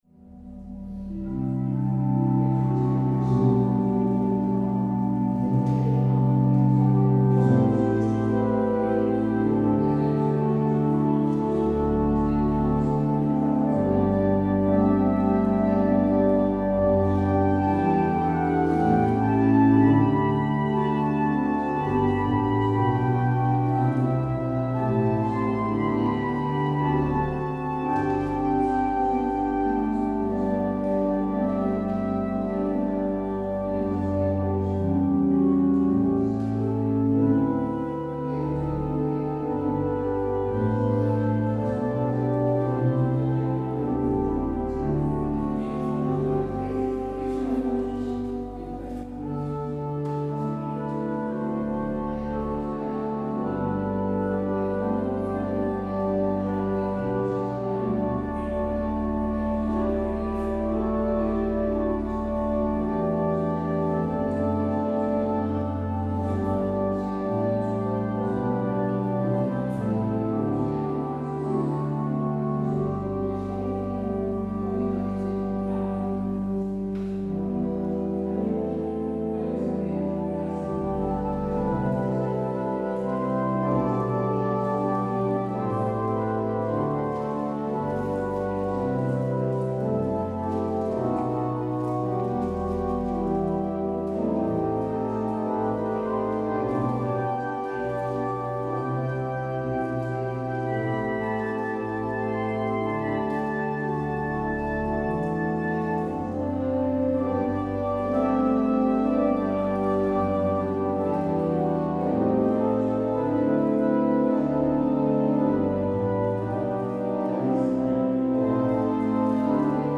Het openingslied is: Psalm 138: 1, 2 en 4.
Als slotlied hoort u: Gezang 460: 1, 2 en 3.